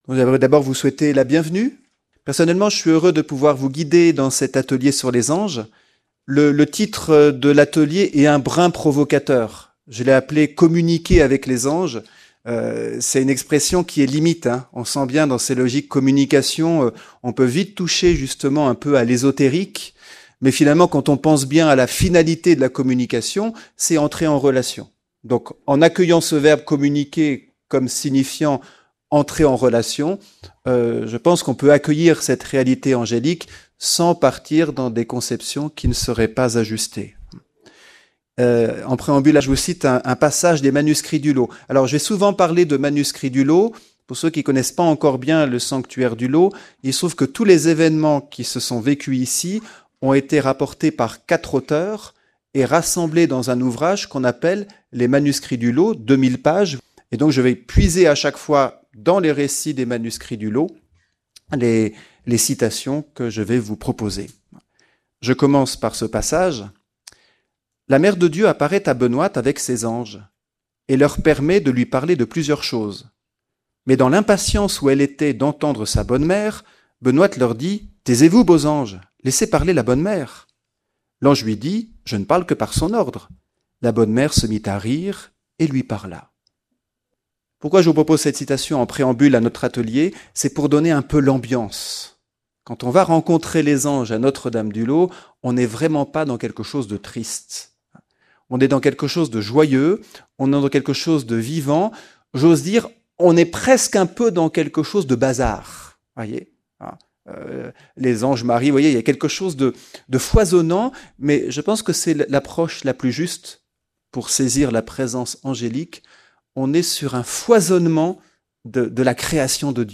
Notre Dame du Laus - Festival Marial